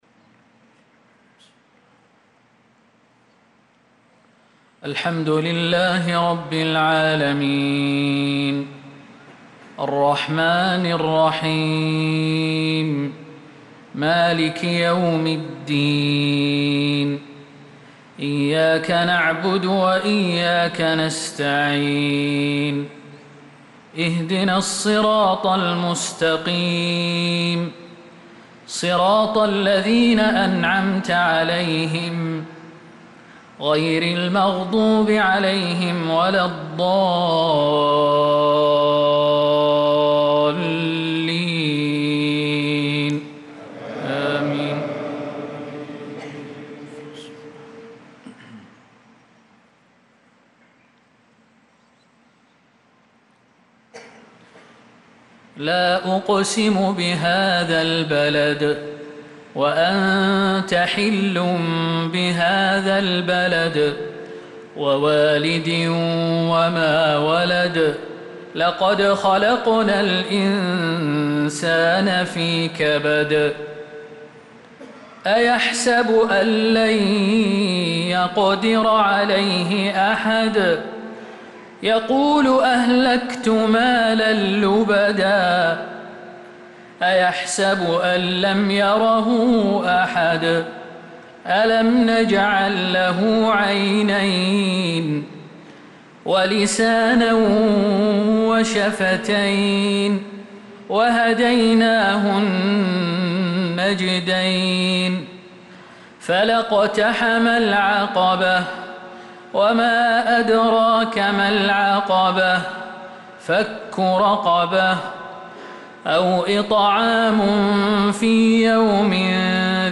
صلاة العشاء للقارئ خالد المهنا 28 ذو القعدة 1445 هـ